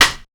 134TTCLAP2-R.wav